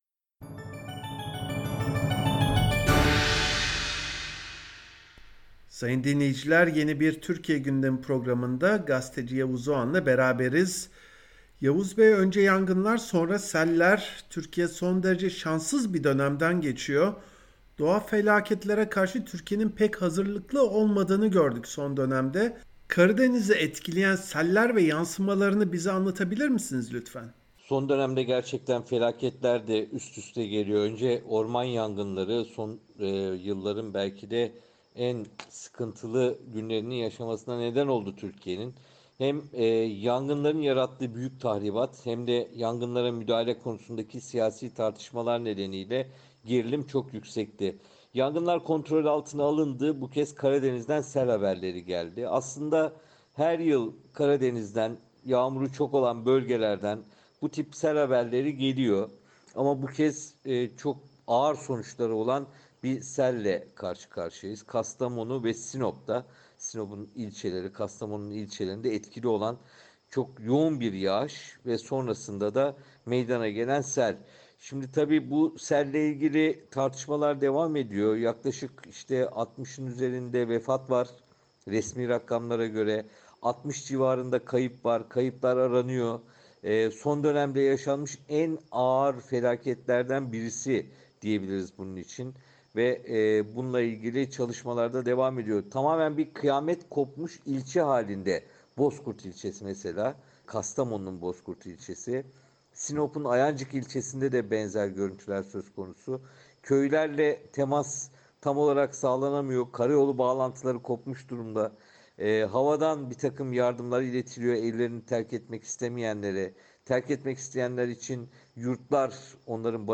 Gazeteci Yavuz Oğhan Akdeniz ve Ege bölgelerindeki orman yangınlarından Karadeniz’deki sellere kadar ölümcül doğal afetler hakkında konuşulanları SBS Türkçe için değerlendirdi.